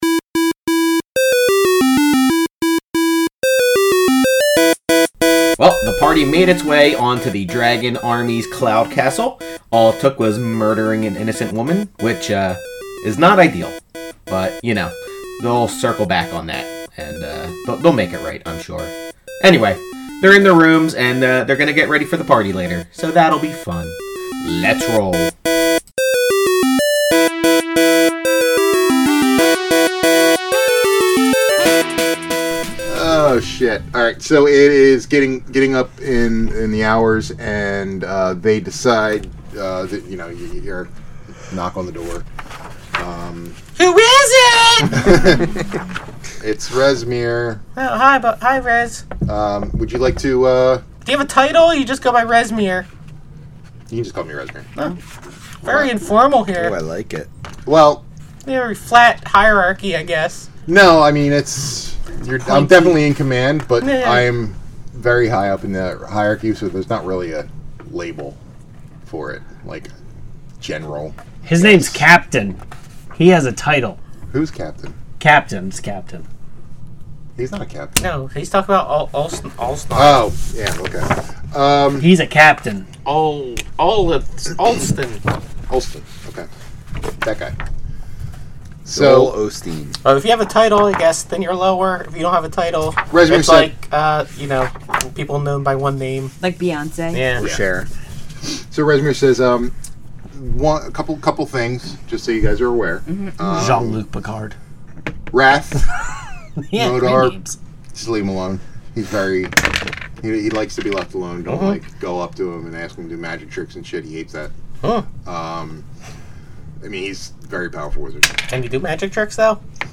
An actual play D&D podcast with a rotating cast of players and dungeon masters. We started off with the campaign “Tyranny of Dragons” and “Curse of Strahd”.